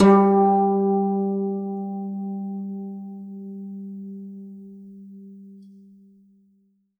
52-str08-zeng-g2.aif